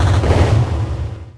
Techmino / media / effect / chiptune / clear_6.ogg